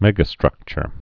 (mĕgə-strŭkchər)